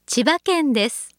ホームページ作成で利用できる、さまざまな文章や単語を、プロナレーターがナレーション録音しています。